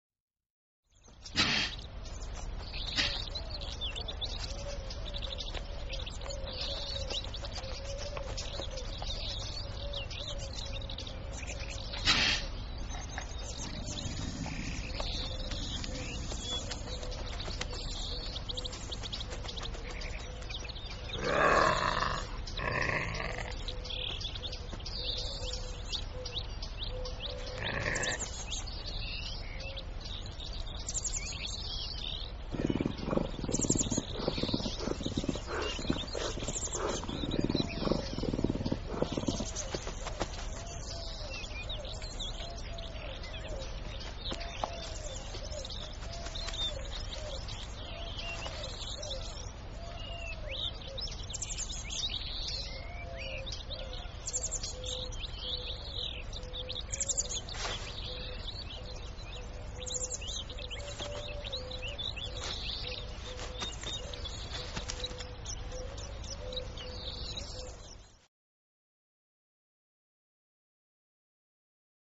Sonidos del Lince Iberico.mp3